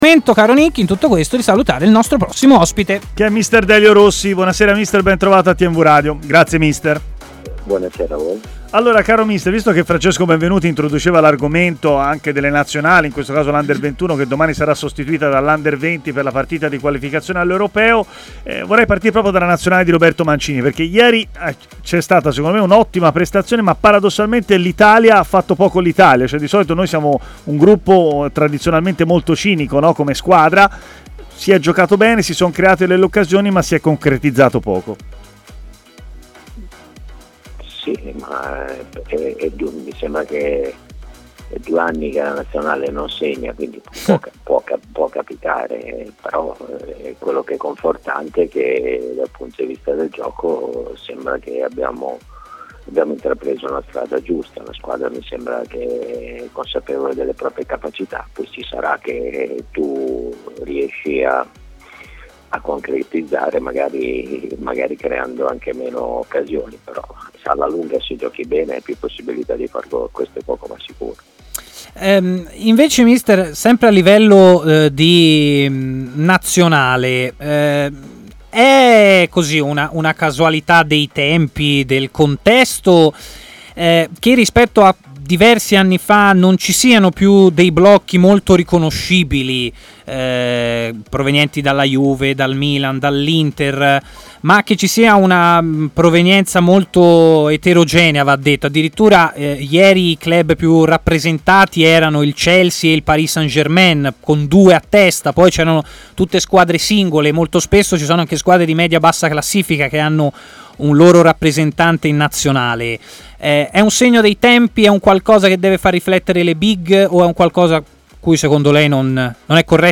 L'allenatore Delio Rossi è intervenuto in diretta a Stadio Aperto, trasmissione di TMW Radio